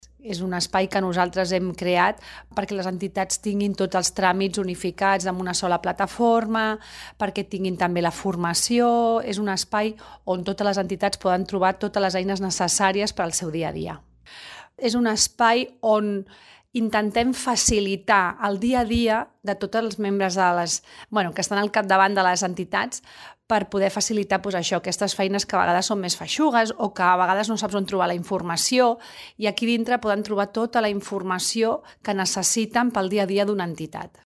Àngels Soria, regidora de Teixit Associatiu